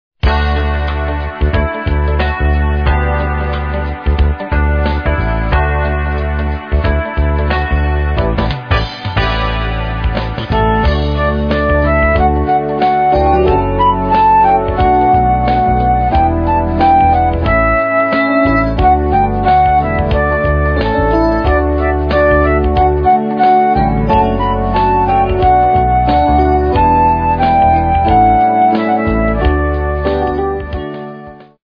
piesne – noty,